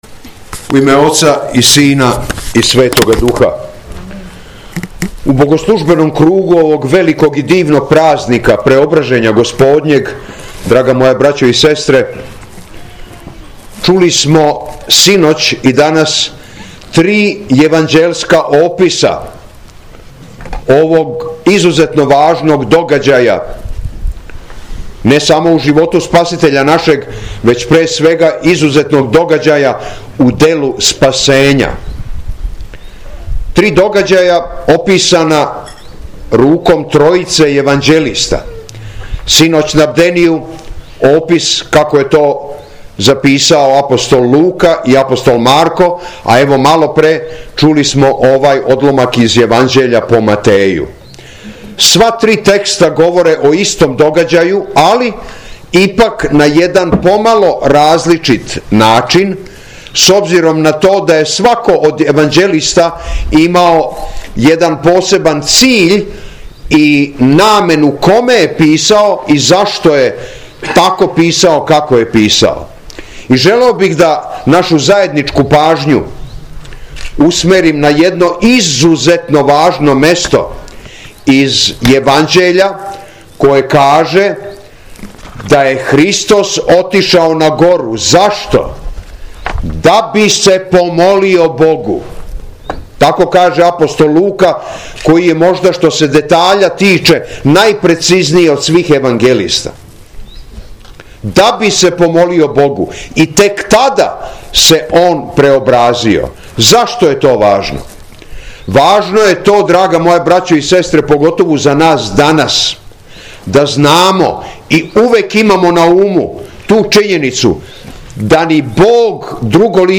СВЕТА АРХИЈЕРЕЈСКА ЛИТУРГИЈА У МАНАСТИРУ БЛАГОВЕШТЕЊЕ КОД СТРАГАРА - Епархија Шумадијска
Беседа